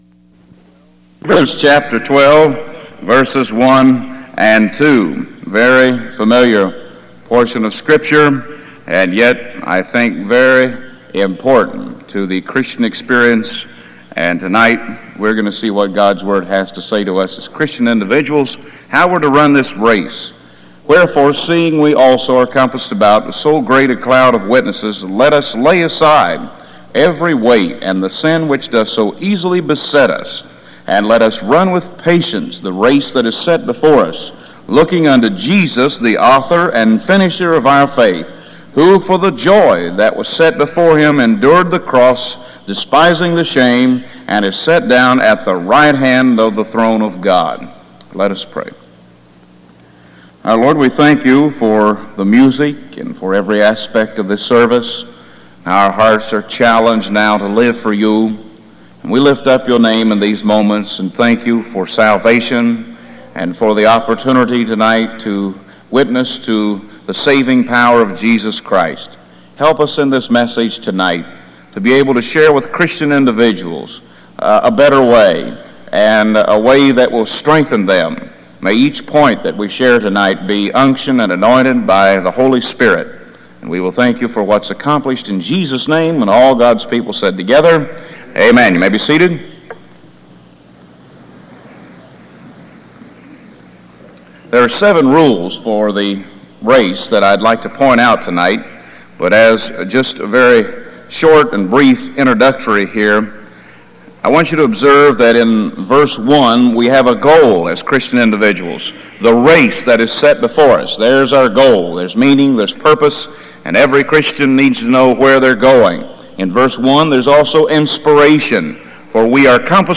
Houghton Camp Meeting: 1982 District Conference- Hebrews 12:1-2
Message on Hebrews 12:1-2